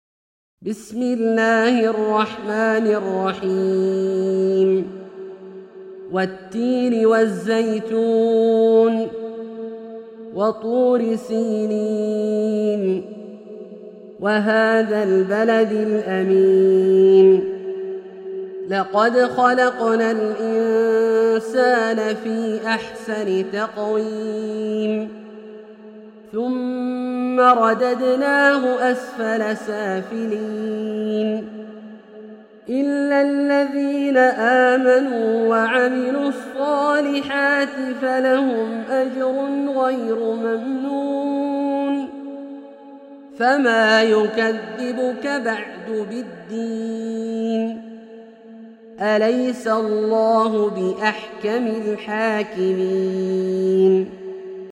سورة التين - برواية الدوري عن أبي عمرو البصري > مصحف برواية الدوري عن أبي عمرو البصري > المصحف - تلاوات عبدالله الجهني